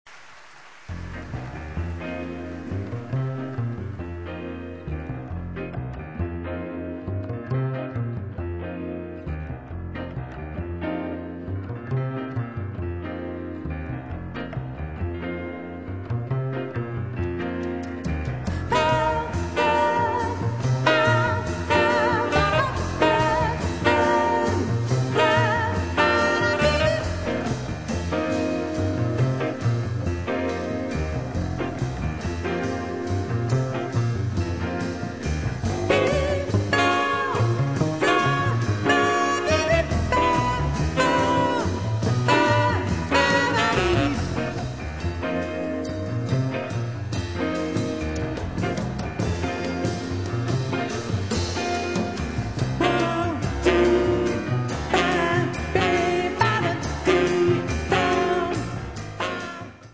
romanzo con pianoforte jazz
Voce
Sassofono
Piano
Chitarra
Basso
Batteria
accompagnata da un buon ensemble di musicisti toscani